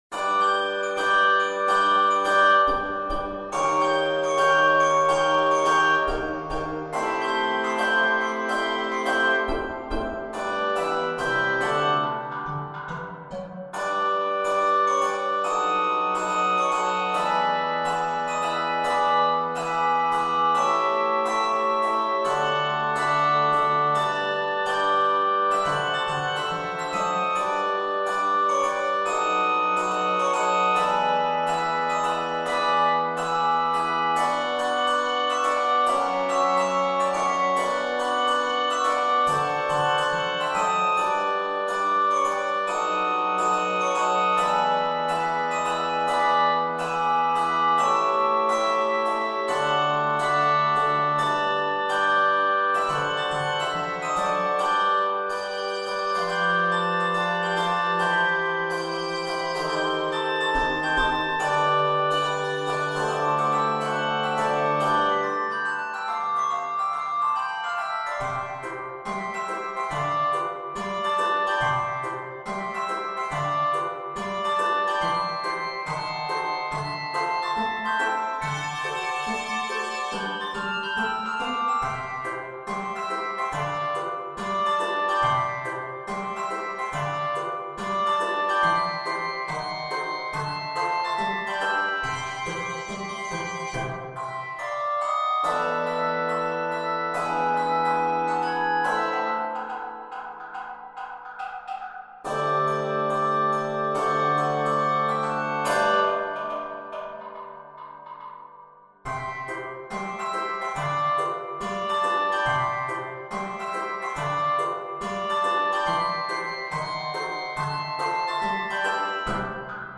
Begins in G Major, then modulates to C Major.